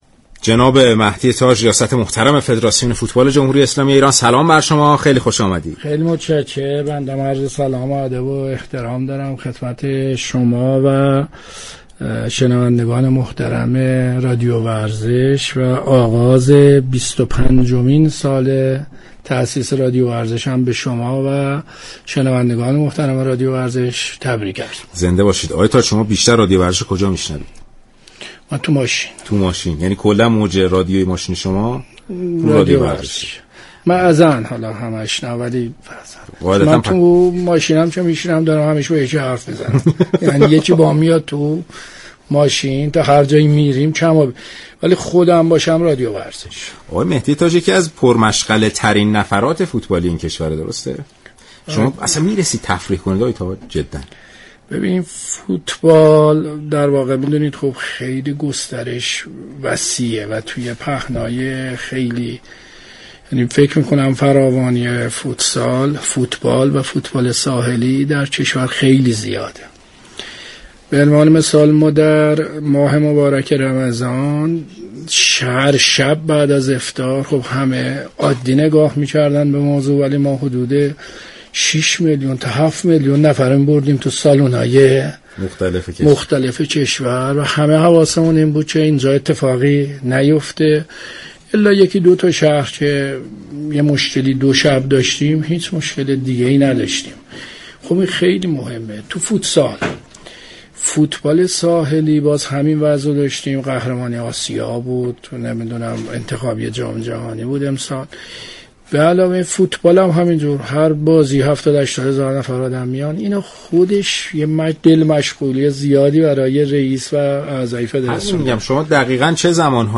مهدی تاج، رئیس فدراسیون فوتبال در گفت‌و‌گو با رادیو ورزش سالروز تولد این شبكه و عید سعید قربان را تبریك گفت و پیام آور خبرهای خوب فوتبالی بود.